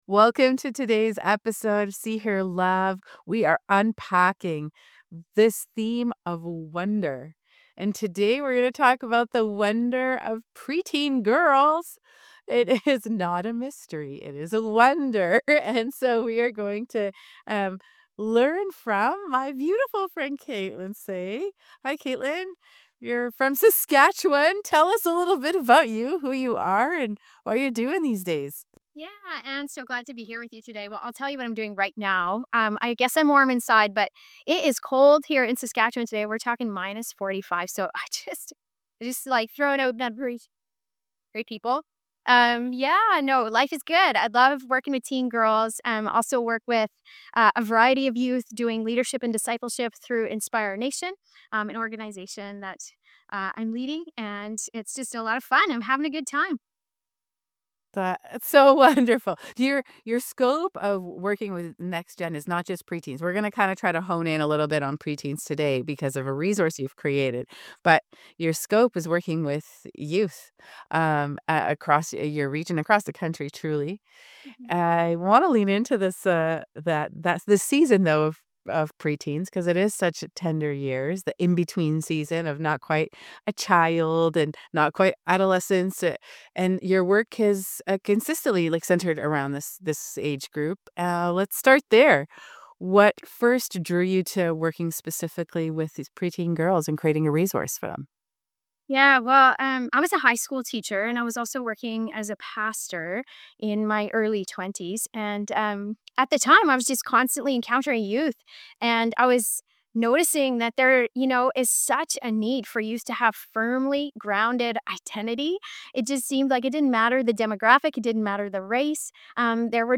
If you care about raising confident, Christ-rooted girls who know who they are and why they matter, this conversation will open your eyes.